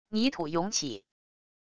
泥土涌起wav音频